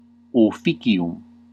Ääntäminen
Vaihtoehtoiset kirjoitusmuodot (vanhentunut) dutie (vanhahtava) dooty Synonyymit obligation tax commitment Ääntäminen US : IPA : [ˈdju.ti] UK : IPA : /ˈdjuː.ti/ US : IPA : /duːɾi/ Tuntematon aksentti: IPA : /ˈdu.ti/